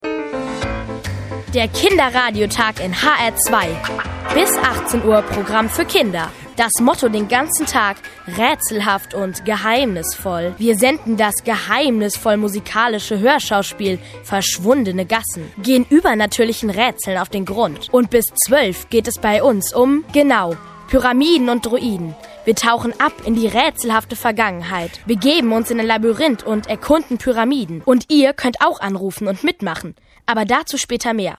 junge deutsche Sprecherin für Rundfunksendungen und Hörspiele.
Junge Stimme
Sprechprobe: Industrie (Muttersprache):
young female german voice over artist